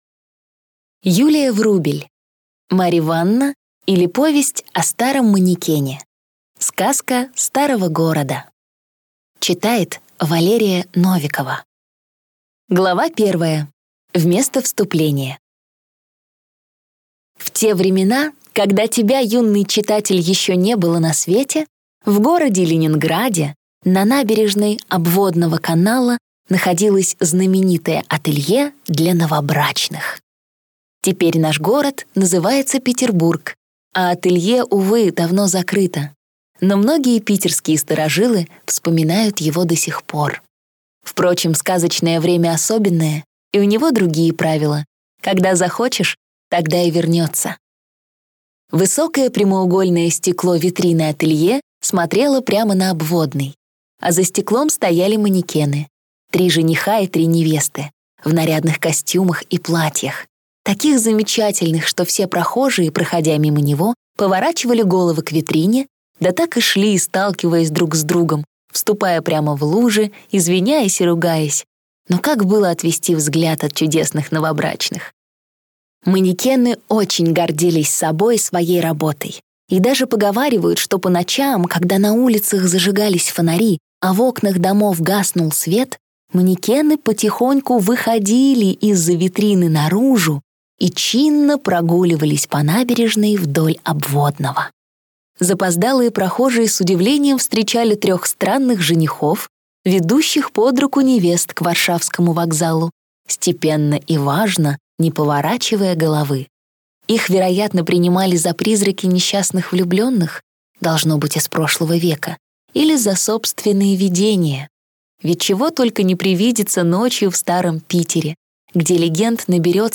Аудиокнига Мариванна, или Повесть о старом манекене. Сказка старого города | Библиотека аудиокниг